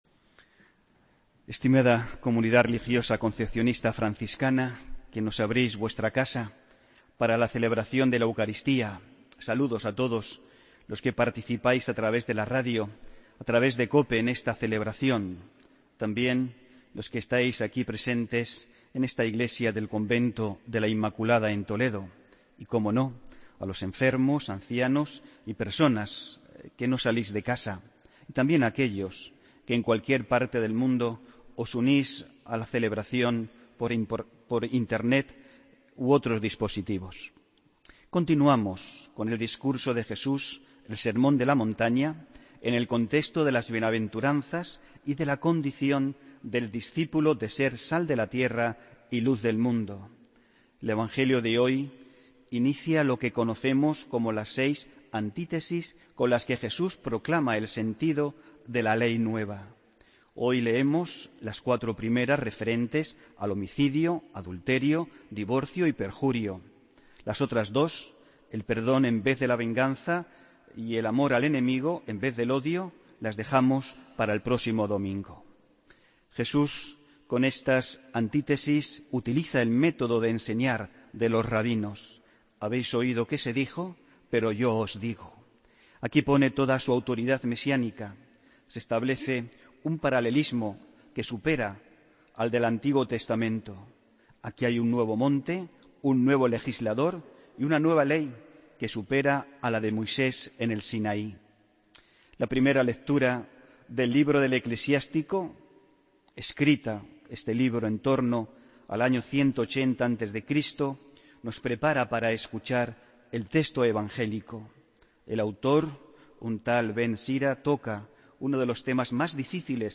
Homilía del domingo 12 de febrero de 2017